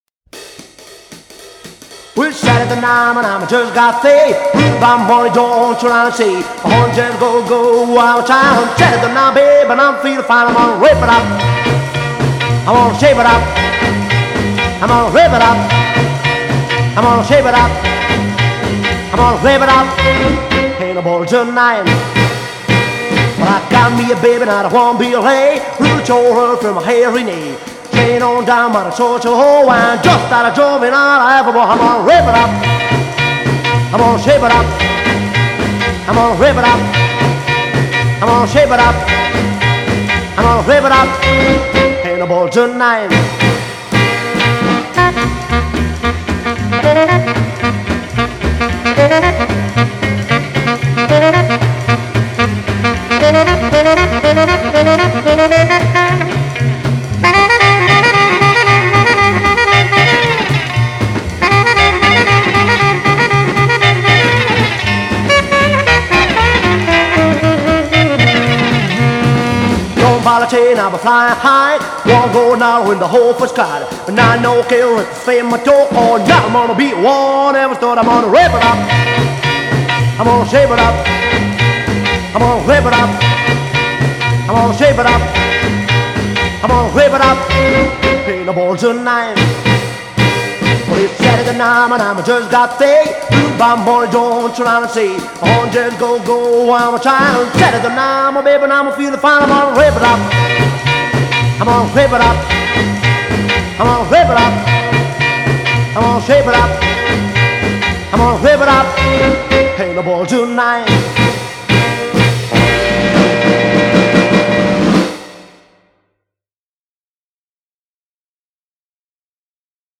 Genre: Pop, Jazz, Rock'n'roll